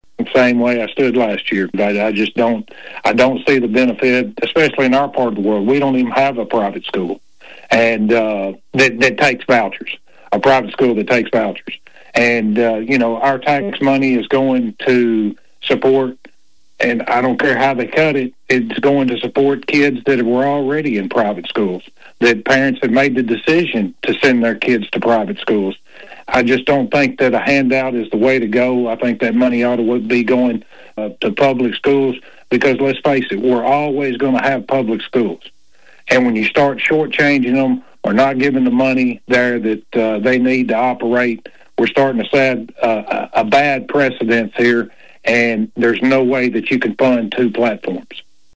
State Representative Tandy Darby tells Thunderbolt News his feelings on the proposed vouchers…